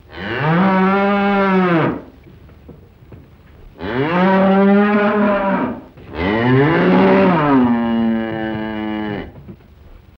Bull | Sneak On The Lot